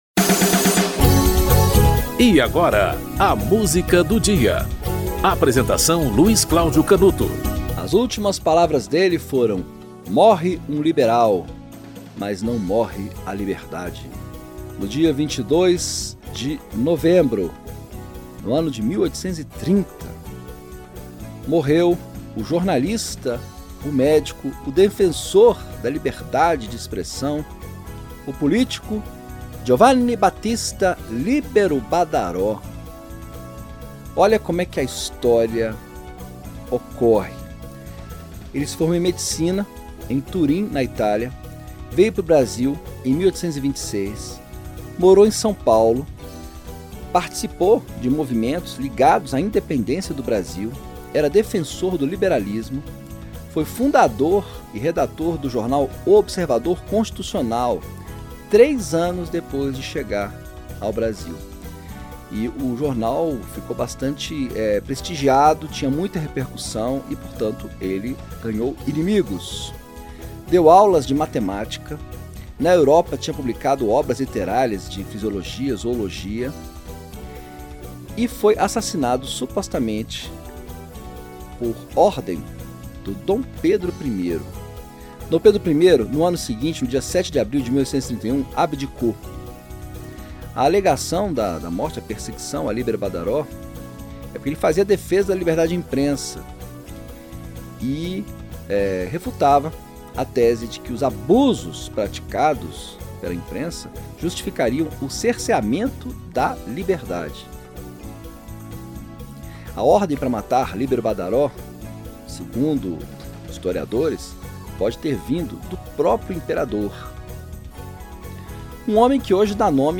Pablo Heras-Casado e Filarmônica de Berlim - Abertura As Hébridas (Mendelssohn)